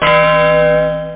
home *** CD-ROM | disk | FTP | other *** search / MACD 5 / MACD 5.bin / workbench / time / clock / bonus / glocke ( .mp3 ) < prev Amiga 8-bit Sampled Voice | 1993-03-28 | 8.3 KB | 1 channel | 7,159 sample rate | 1 second
glocke.mp3